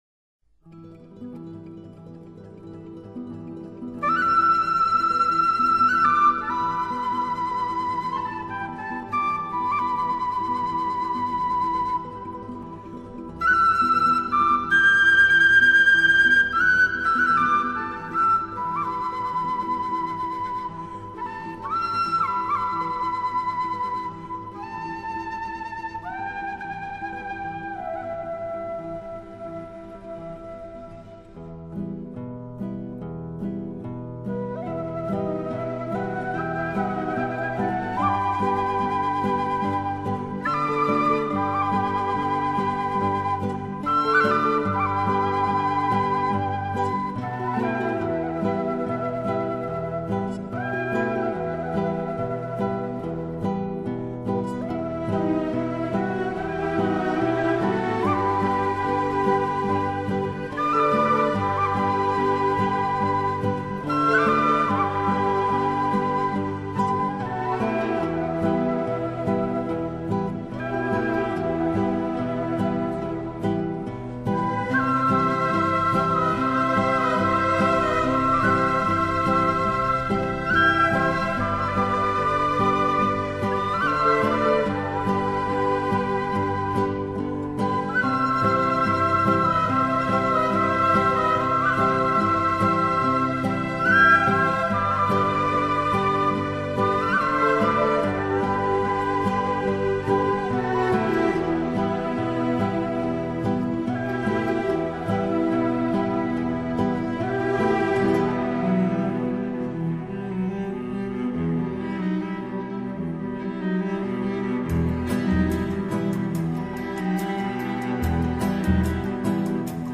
整张唱片音色温暖，乐器质感自然、突出， 低频丰润澎湃，还有很好的空间感。
演奏/唱：24位伦敦最优秀的大提琴手